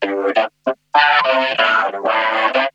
VOC DOODUP 1.wav